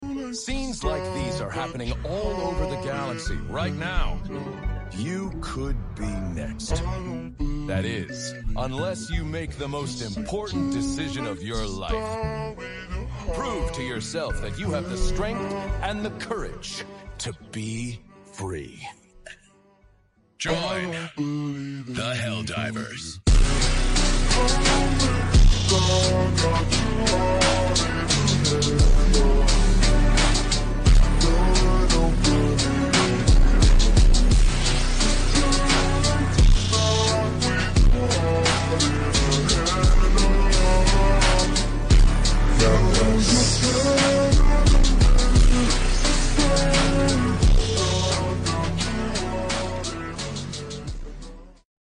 slowed x reverb